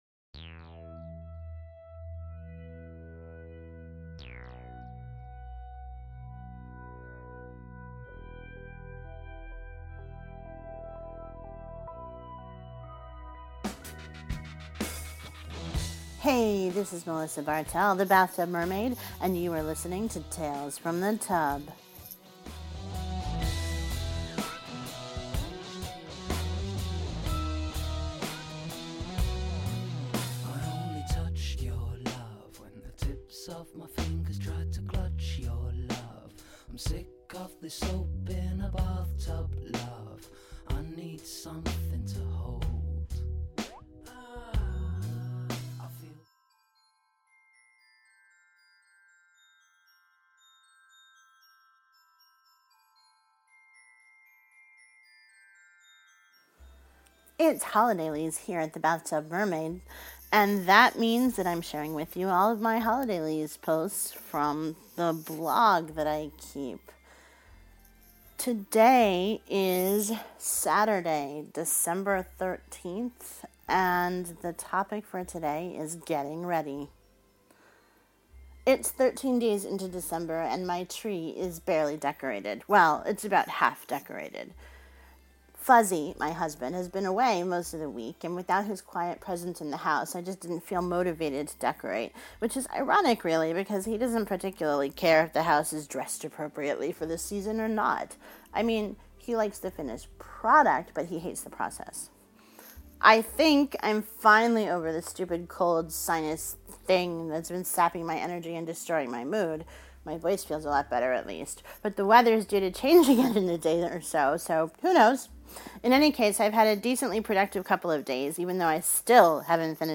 The standard opening song is “Soap in a Bathtub,” by Stoney. The standard closing song is “You Can Use My Bathtub, by Little Thom.